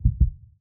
heartbeat_single.mp3